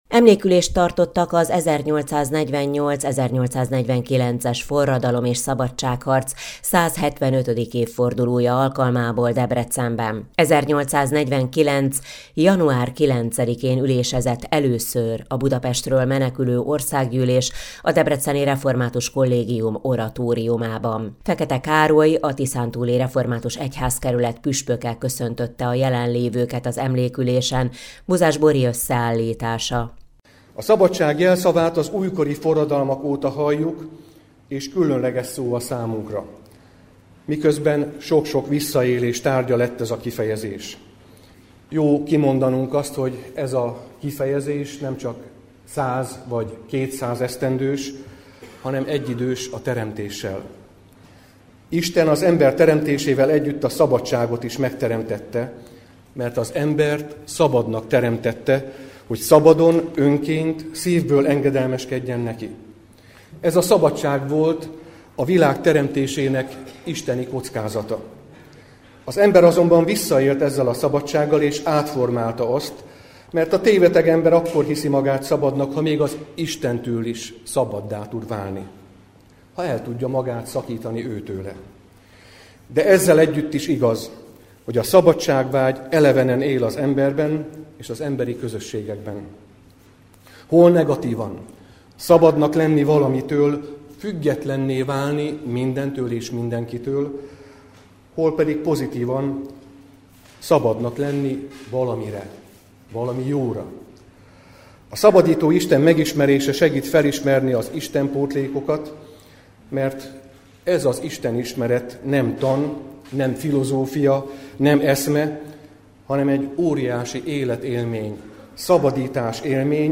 Emlékülést tartottak a debreceni Református Kollégiumban
Emlékülést tartottak az 1848/1849-es forradalom és szabadságharc 175. évfordulója alkalmából Debrecenben a Református Kollégiumban. 1849. január 9-én ülésezett először a Budapestről menekülő Országgyűlés, a Debreceni Református Kollégium Oratóriumában. Fekete Károly, a Tiszántúli Református Egyházkerület püspöke köszöntötte a jelenlévőket az emlékülésen.